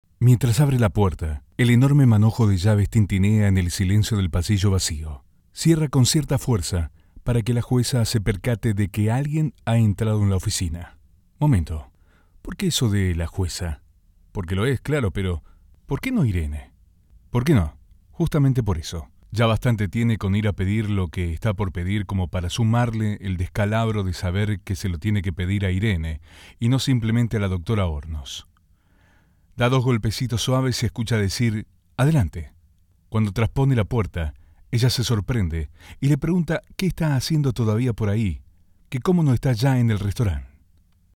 Audio Book Voice Over Narrators
Adult (30-50)